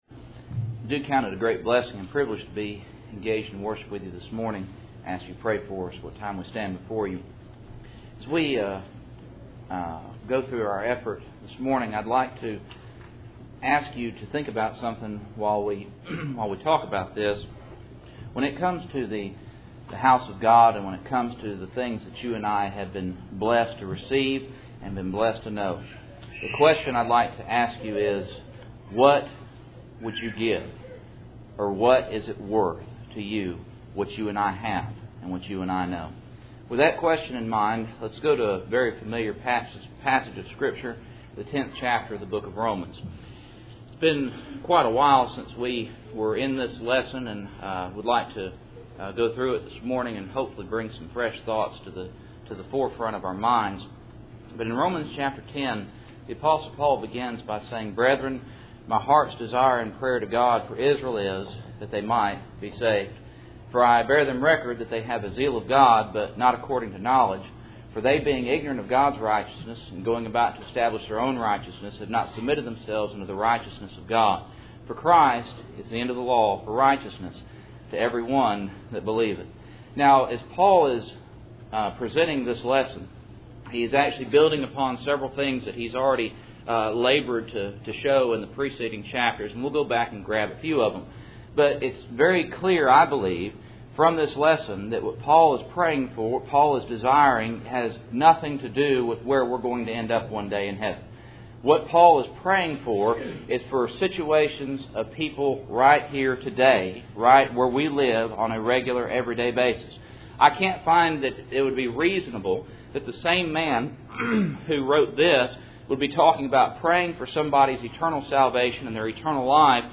Passage: Romans 10:1-4 Service Type: Cool Springs PBC Sunday Morning